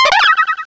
pokeemerald / sound / direct_sound_samples / cries / mimejr.aif